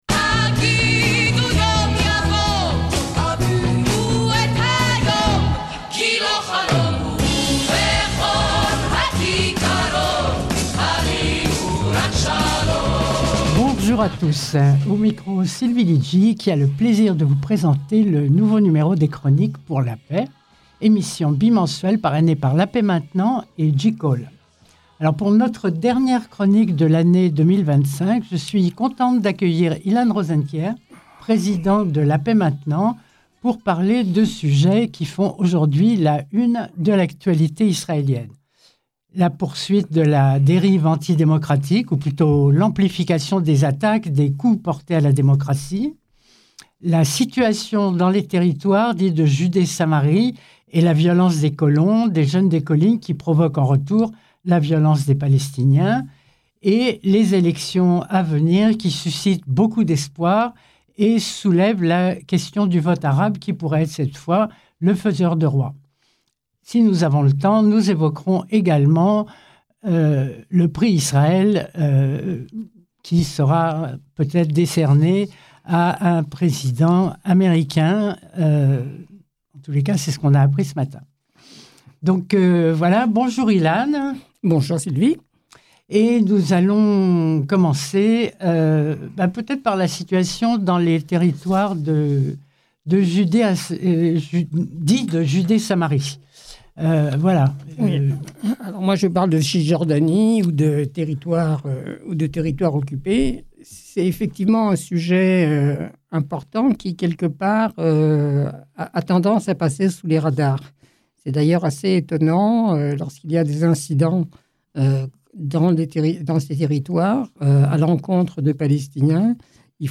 Dans le cadre de Chroniques pour la Paix, émission bimensuelle sur Radio Shalom,